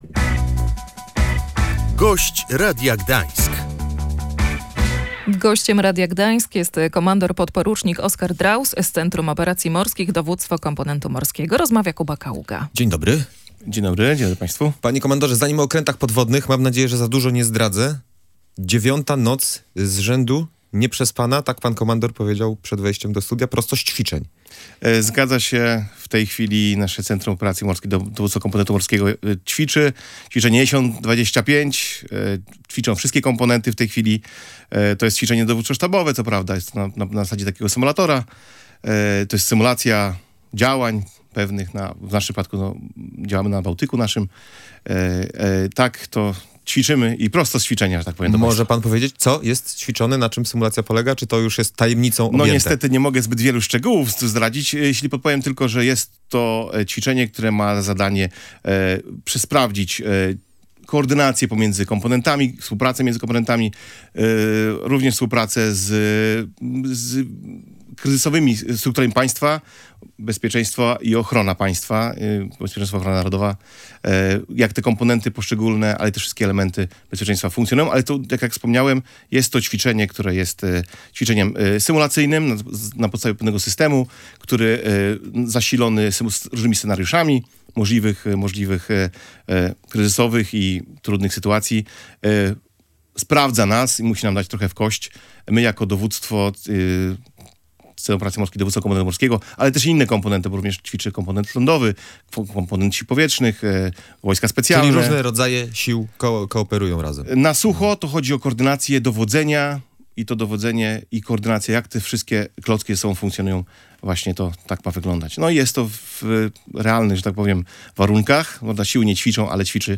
Gość Radia Gdańsk